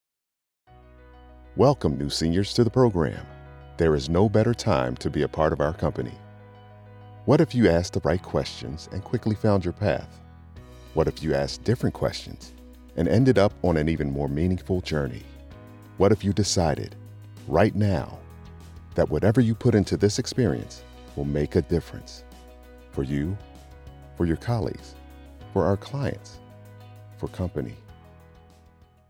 Male voice actor
Corporate, Believable, Inspirational, Deep